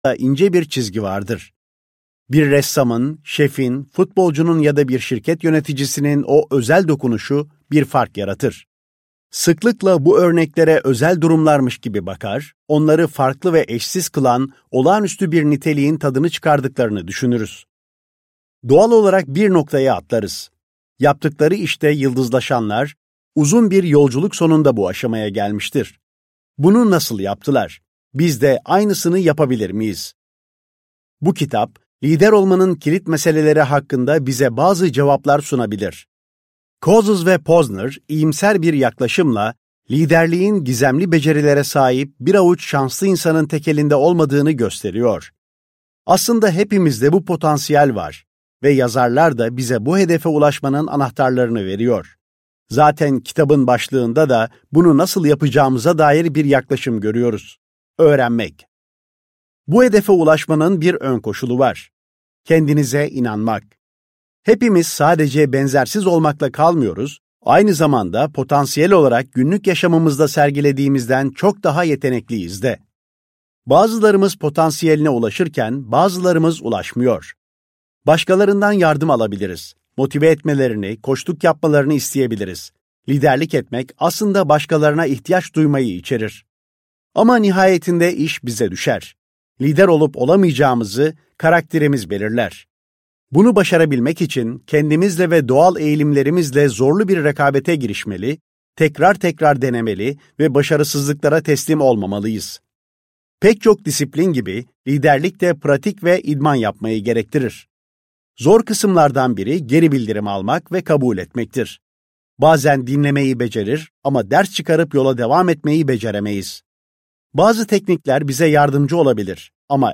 Liderliği Öğrenmek - Seslenen Kitap